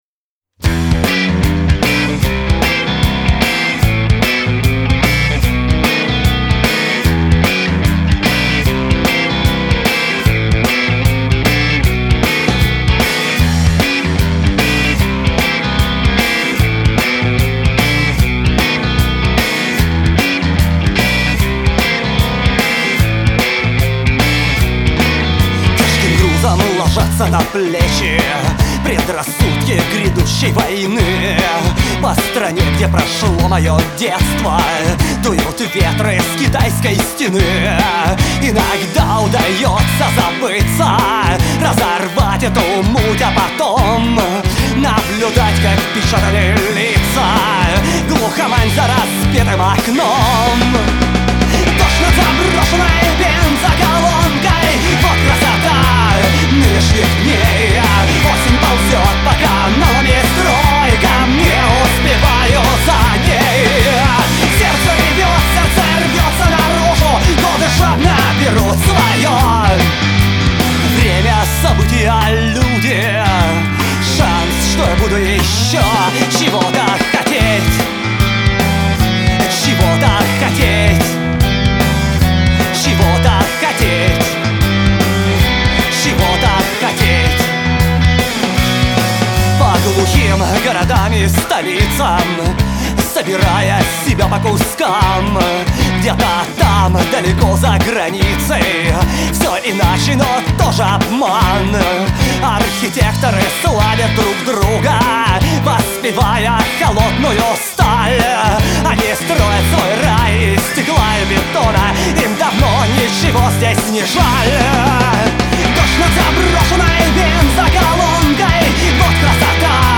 вокал, гитара